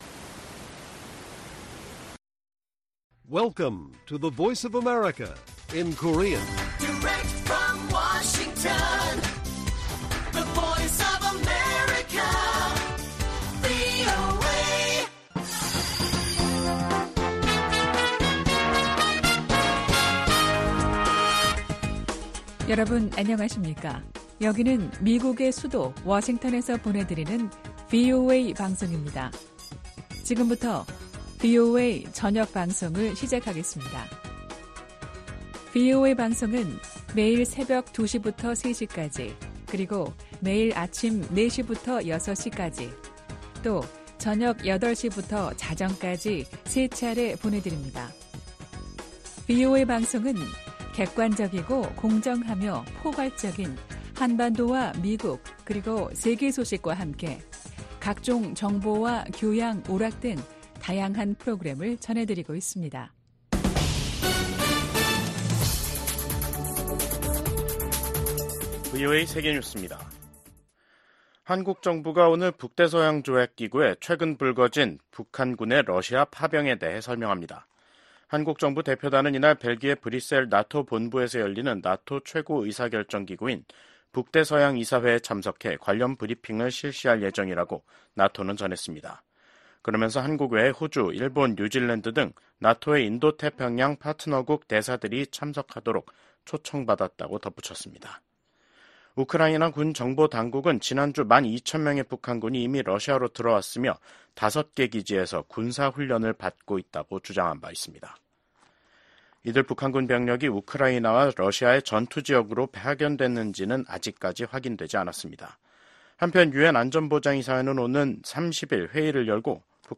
VOA 한국어 간판 뉴스 프로그램 '뉴스 투데이', 2024년 10월 28일 1부 방송입니다. 러시아에 파견된 북한군의 역할에 관심이 집중되고 있는 가운데 미한외교 안보 수장이 워싱턴에서 ‘2+2회담’을 갖고 해당 현안을 논의합니다. 미국과 한국, 일본의 안보 수장들이 워싱턴에서 만나 북한군의 러시아 파병에 깊은 우려를 나타냈습니다.